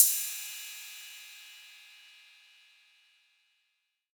808CY_7_Orig_ST.wav